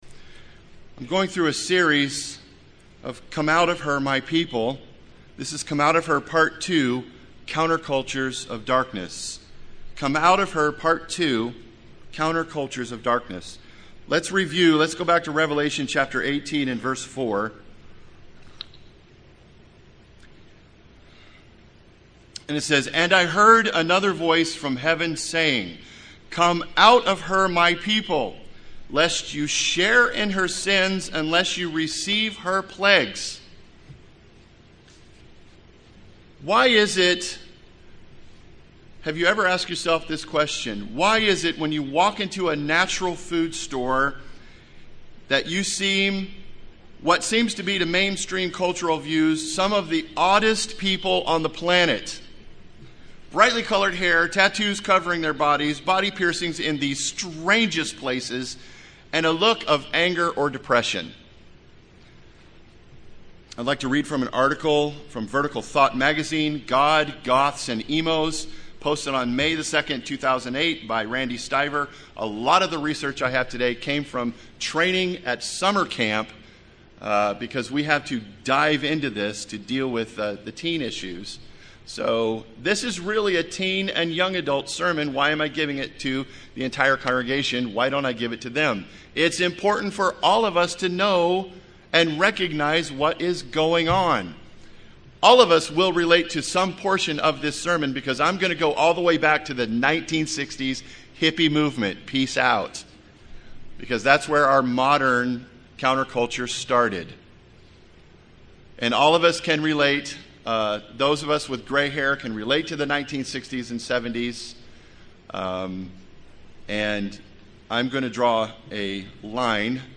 This is the second in a series of sermons on the cultures of this world and how we should come out of this world. Our true counterculture was started by Jesus Christ and God the Father from the beginning of the universe.